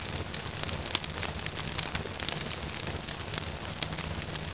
candle.wav